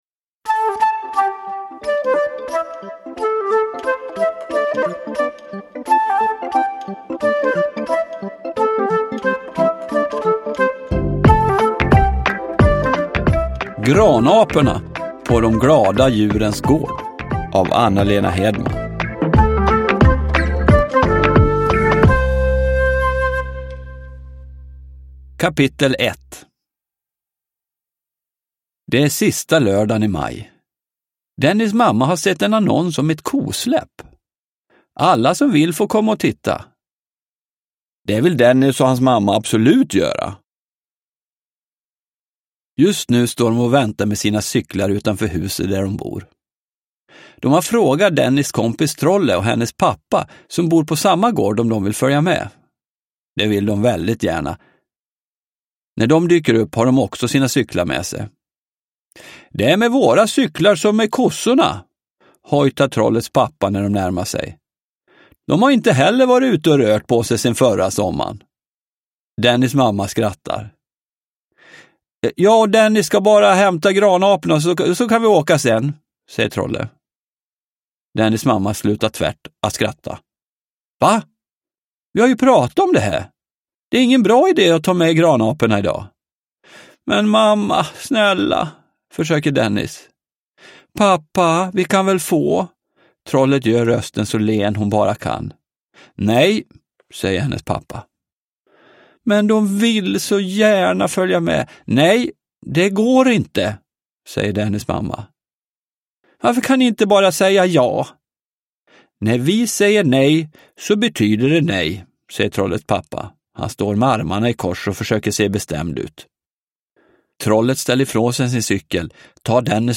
Granaporna på de glada djurens gård – Ljudbok – Laddas ner